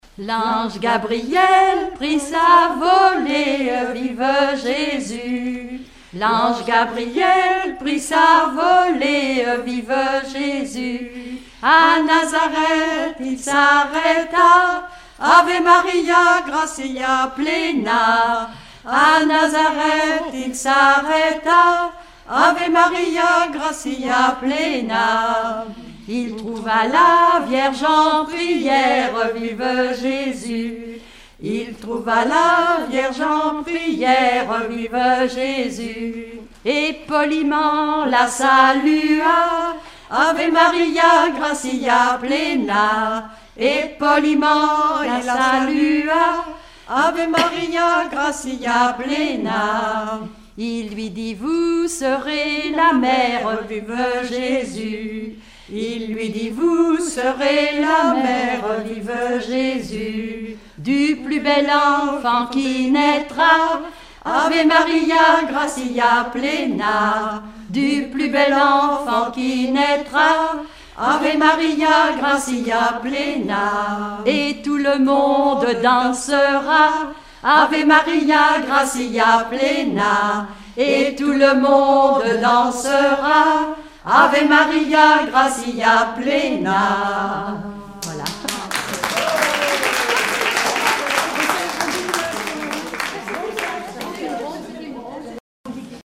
prière, cantique
Regroupement de chanteurs du canton
Pièce musicale inédite